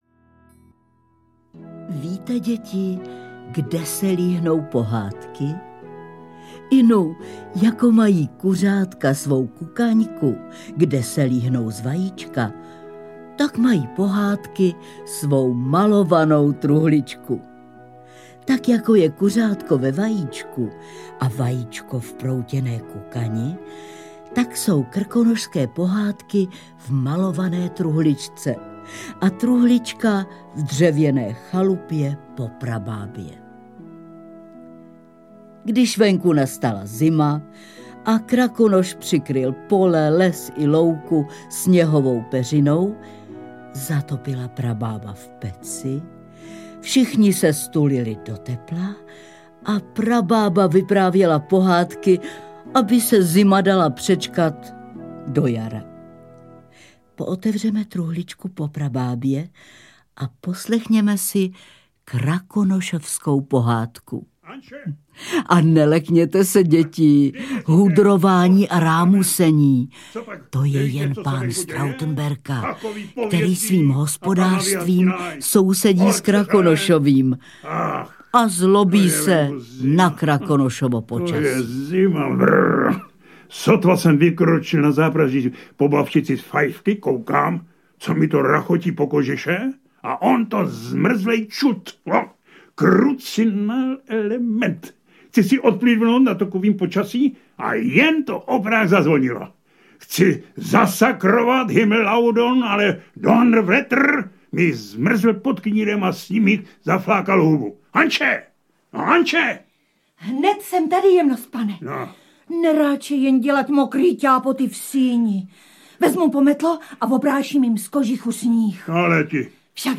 Ukázka z knihy
Komplet pohádek Marie Kubátové obsahuje především tři originální alba příběhů o Krakonošovi, Trautenberkovi, Kubovi, Ančeti a hajném. Jde celkem o osm samostatných dramatizovaných pohádek s řadou skvělých českých herců - Hanou Maciuchovou, Jaroslavem Satoranským, Radoslavem Brzobohatým, Lubomírem Lipským a dalšími.